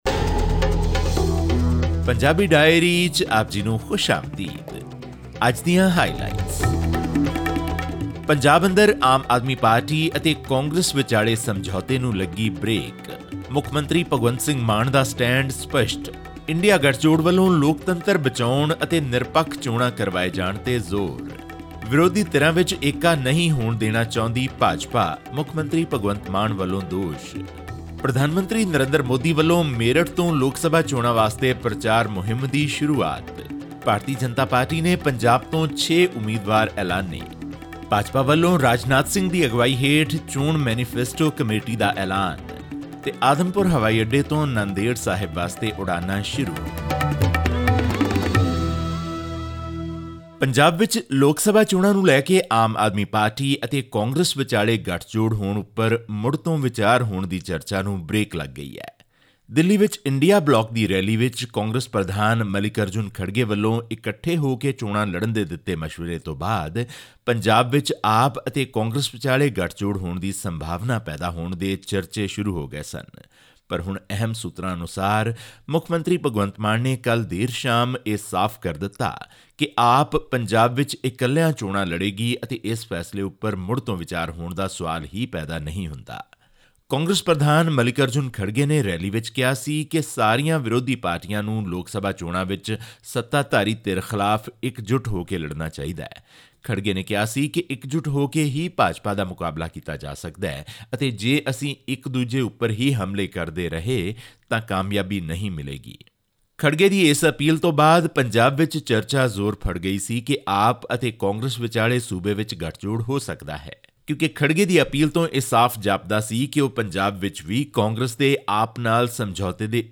ਪਰ ਅਹਿਮ ਸੂਤਰਾਂ ਮੁਤਾਬਿਕ ਮੁੱਖ ਮੰਤਰੀ ਭਗਵੰਤ ਮਾਨ ਨੇ ਦਿੱਲੀ ਰੈਲੀ ਤੋਂ ਬਾਅਦ ਪਾਰਟੀ ਅੰਦਰ ਇਹ ਸਾਫ਼ ਕਰ ਦਿੱਤਾ ਹੈ ਕਿ ‘ਆਪ’ ਪੰਜਾਬ ਵਿੱਚ ਇਕੱਲੇ ਚੋਣਾਂ ਲੜੇਗੀ ਅਤੇ ਇਸ ਫ਼ੈਸਲੇ ’ਤੇ ਮੁੜ ਵਿਚਾਰ ਹੋਣ ਦਾ ਸਵਾਲ ਹੀ ਪੈਦਾ ਨਹੀਂ ਹੁੰਦਾ। ਹੋਰ ਵੇਰਵੇ ਲਈ ਸੁਣੋ ਇਹ ਆਡੀਓ ਰਿਪੋਰਟ...